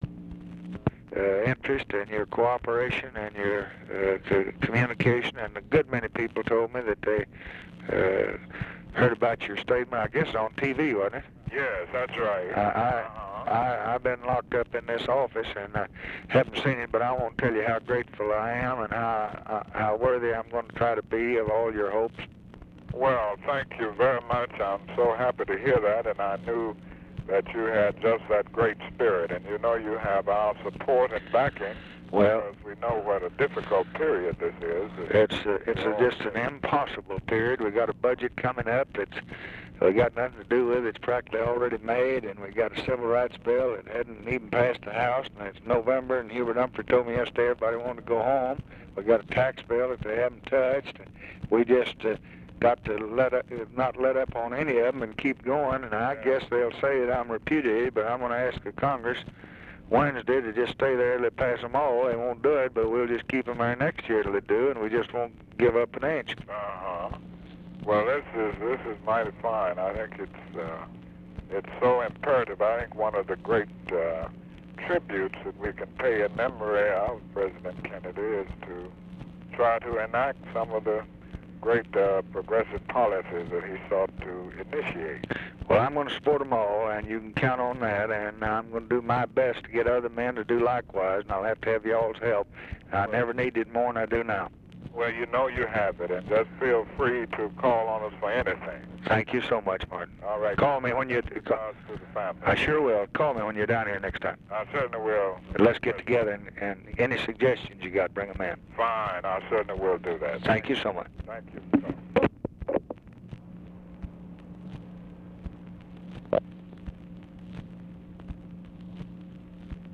On November 25, 1963, three days after President John F. Kennedy was assassinated in Dallas, Tex., the newly installed president, Lyndon B. Johnson, called Dr. Martin Luther King, Jr. (GRS'55, Hon.59). Johnson thanked King for his support and told the civil rights leader, "How worthy I'm going to try to be of all your hopes."